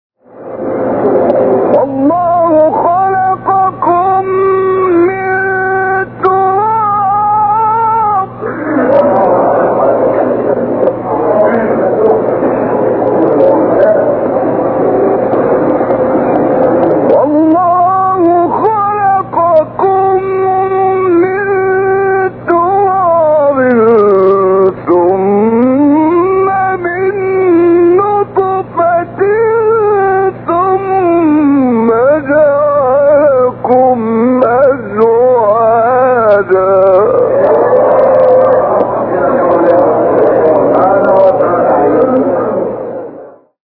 گروه شبکه اجتماعی: نغمات صوتی از قاریان ممتاز مصری ارائه می‌شود.